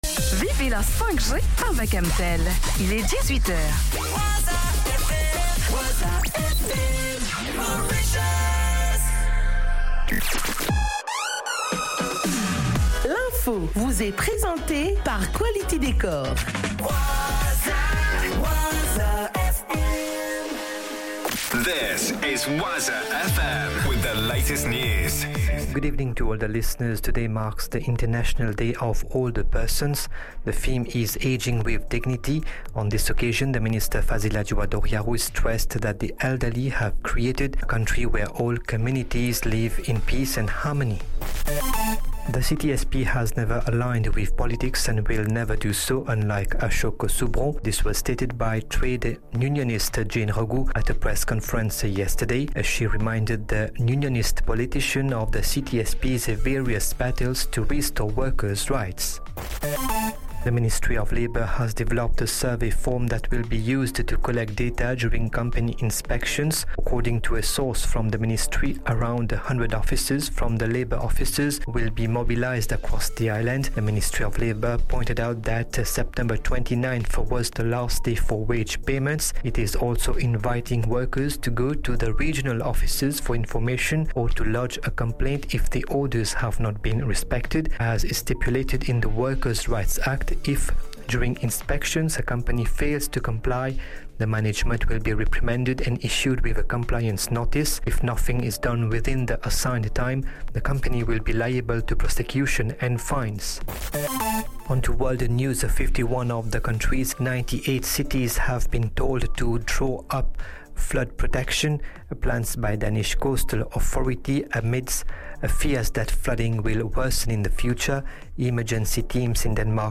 NEWS 18HR - 01 10 24